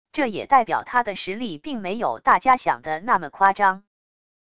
这也代表他的实力并没有大家想的那么夸张wav音频生成系统WAV Audio Player